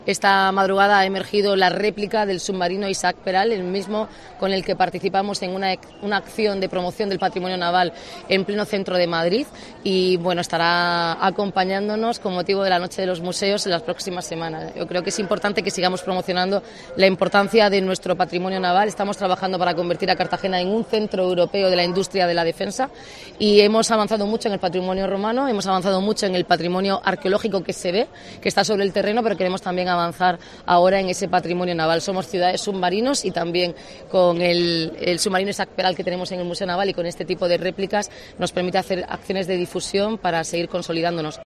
Noelia Arroyo sobre réplica submarino en plaza Juan XIII